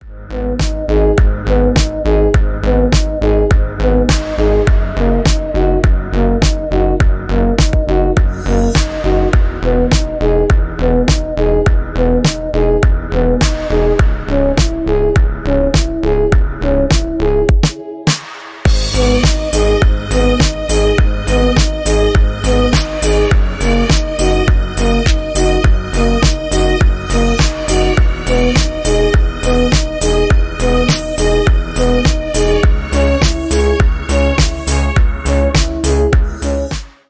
Электроника
громкие